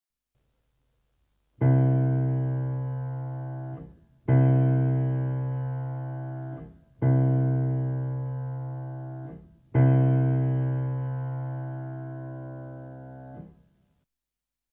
60 Tuning Note - C-String (Cello)